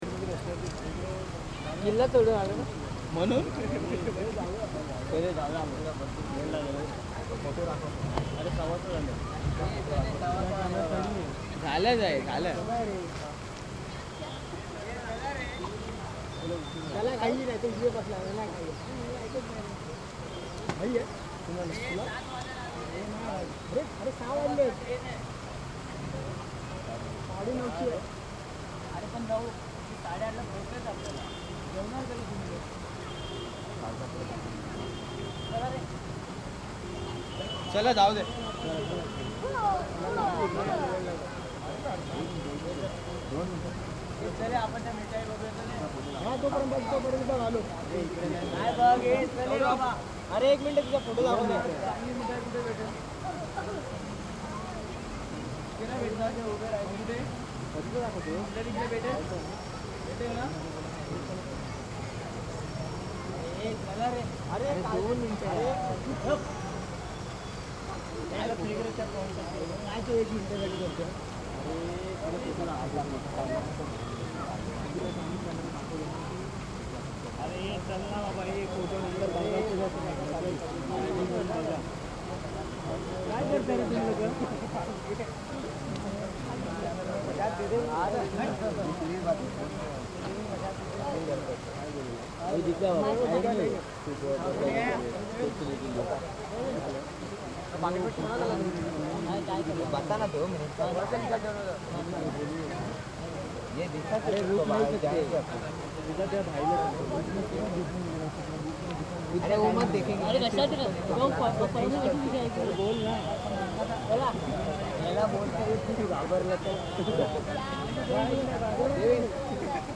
15/12/2013 17:30 Aujourd'hui je me ballade un peu dans Aurangabad. Je vais voir Banyan tree, un arbre vieux de 600 ans, sous lequel est enterré Baba Shah Muzaffar, un saint soufi. Derrière l'arbre se trouvent des mosquées, des bassins, des boutiques, des enfants qui courent partout.